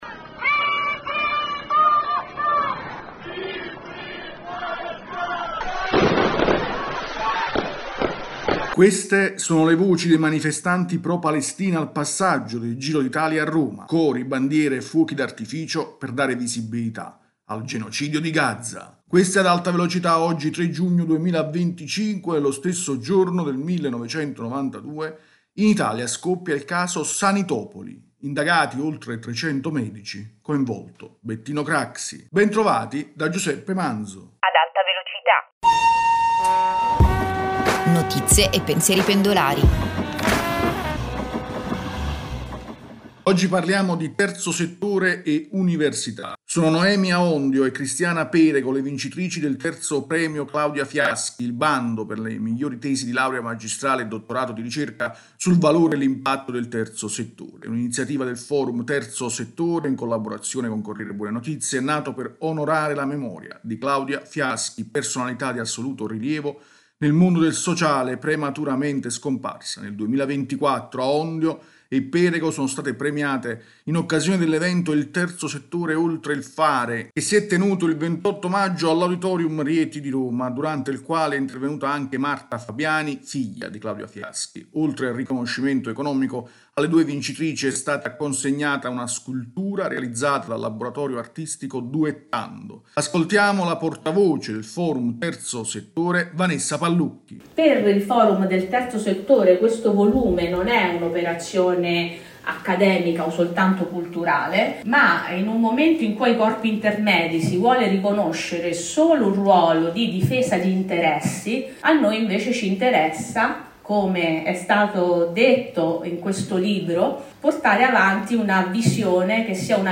[Intro: Queste sono le voci dei manifestanti pro Palestina al passaggio del Giro d’Italia a Roma: cori, bandiere e fuochi d’artificio per dare visibilità al genocidio di Gaza.